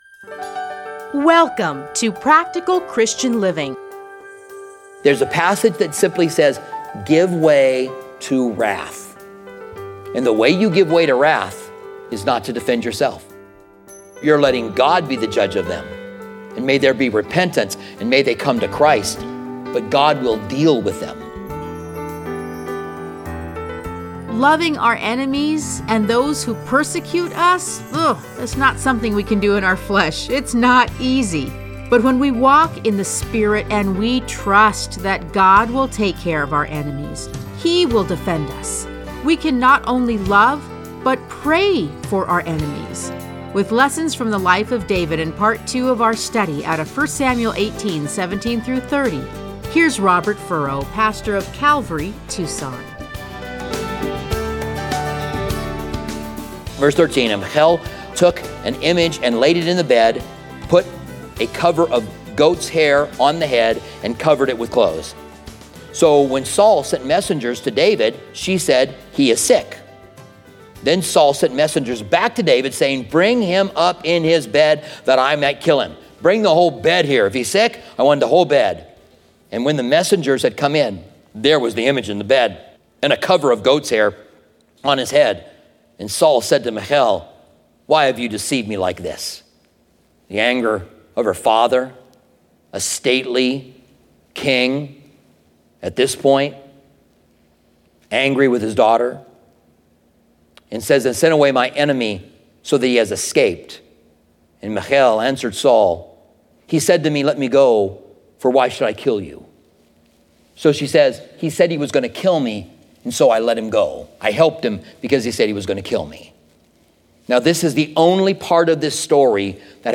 Listen to a teaching from 1 Samuel 18:17-30.